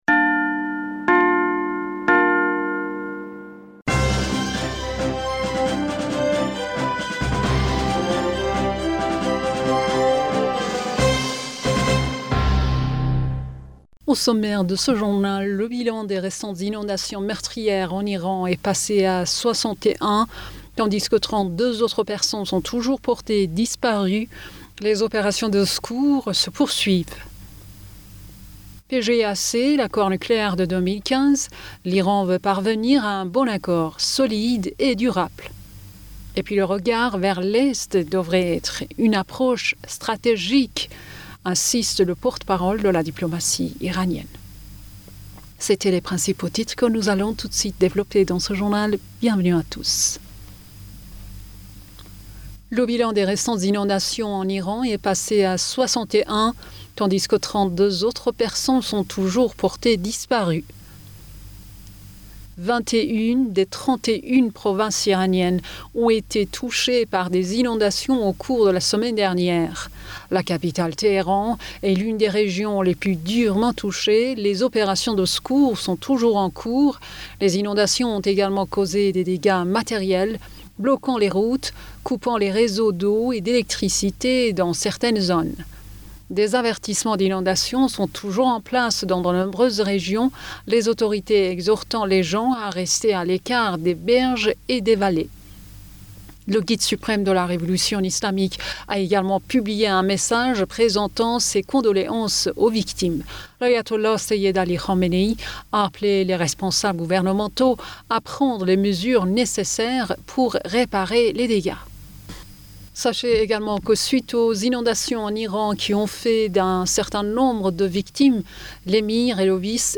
Bulletin d'information Du 31 Julliet